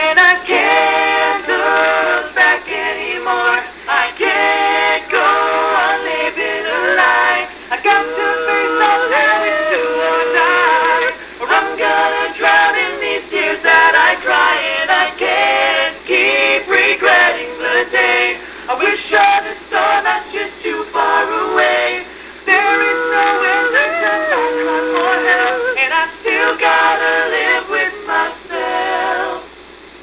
You're celebrity favorites give Fanvasion shout outs!